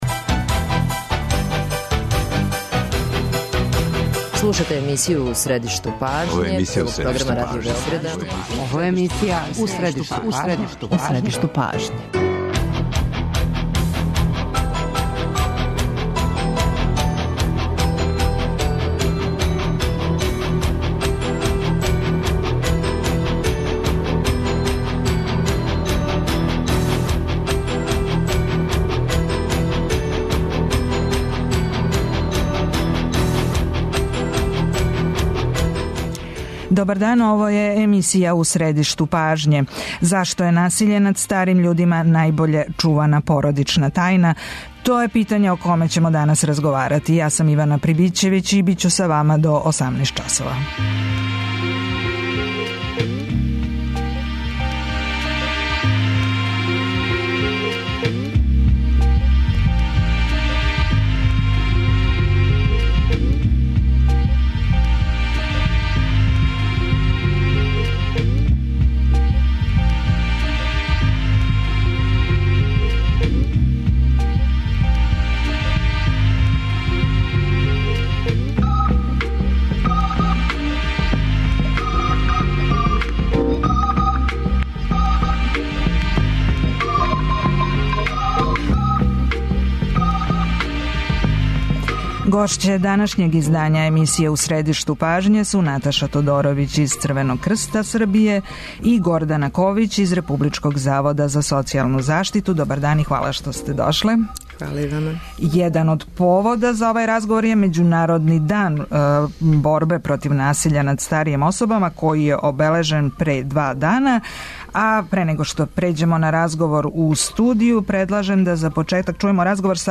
Међународни дан борбе против насиља над старима, обележен пре два дана и у нашој земљи, један је од повода за разговор у емисији.